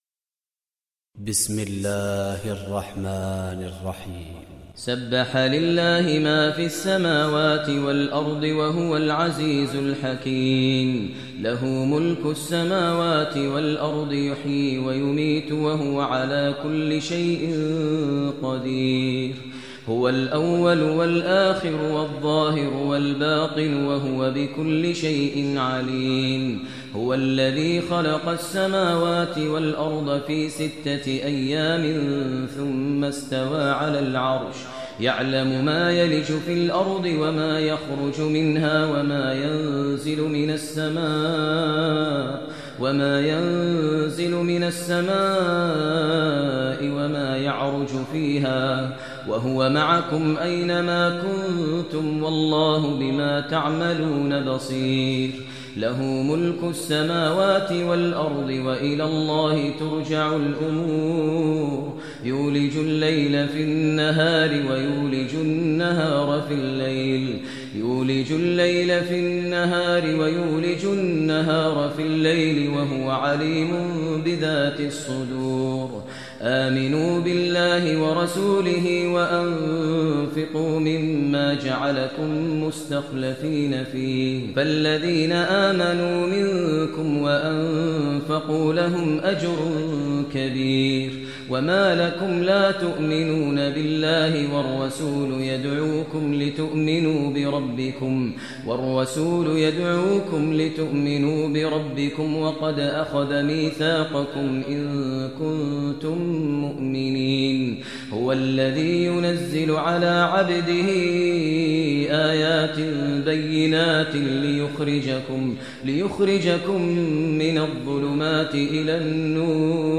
Surah Hadid Recitation by Sheikh Maher al Mueaqly
Surah Hadid, listen online mp3 tilawat / recitation in the voice of Imam e Kaaba Sheikh Maher al Mueaqly.